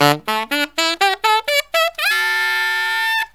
63SAXFALL1-L.wav